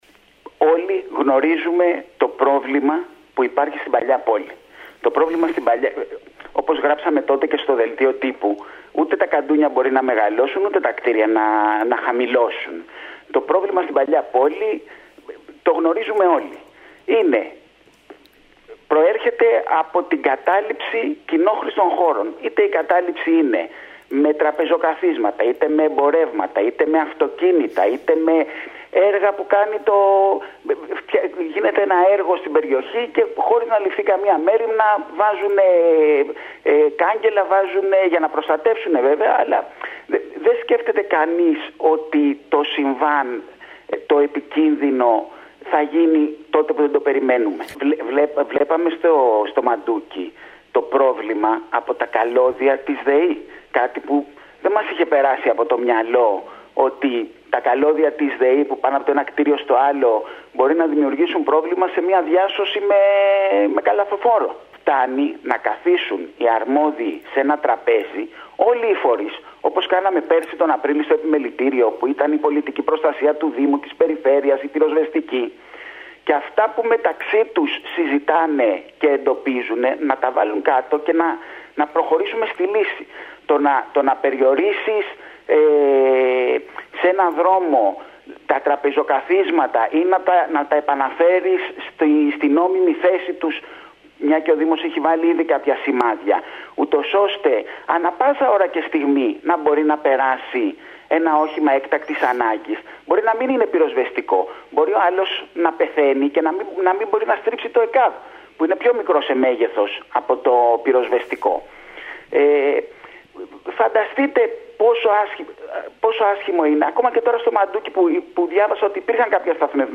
μιλώντας σήμερα στην ΕΡΤ Κέρκυρας με αφορμή το σπίτι που κάηκε στο Μαντούκι